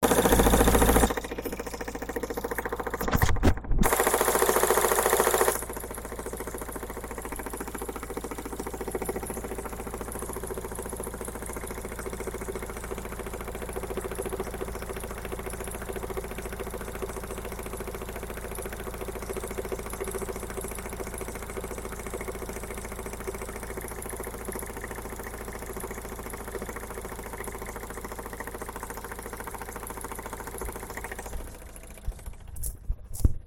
voiture.mp3